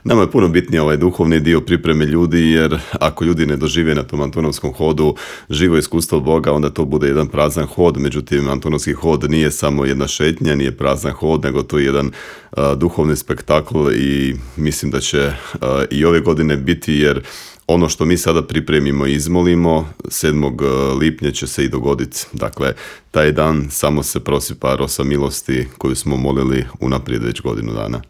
Intervju Media servisa